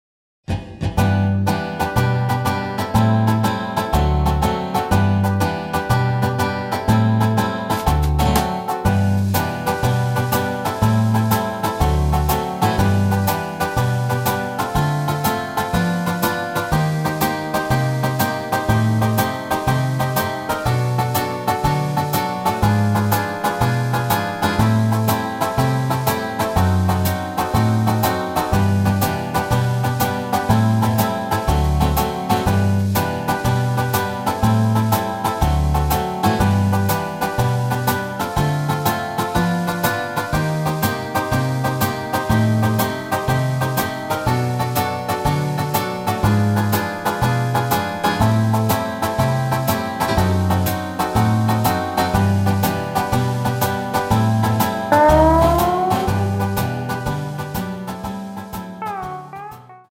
앞부분30초, 뒷부분30초씩 편집해서 올려 드리고 있습니다.